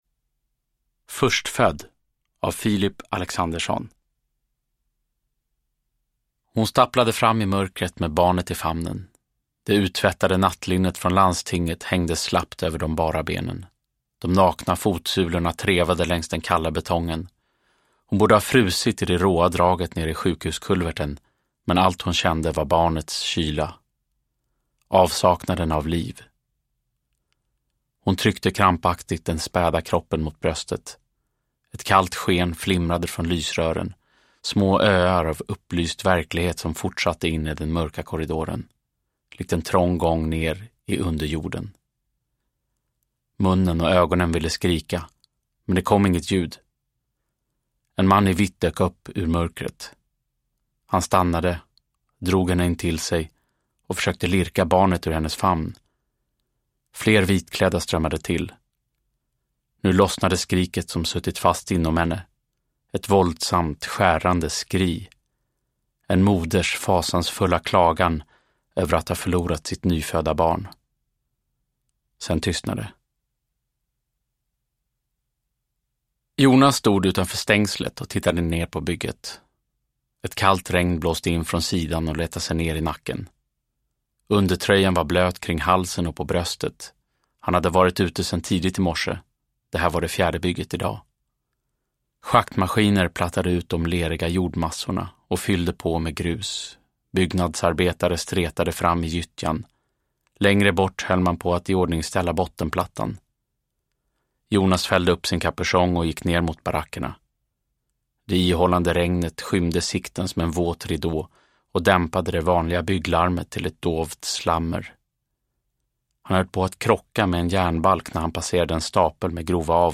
Förstfödd – Ljudbok – Laddas ner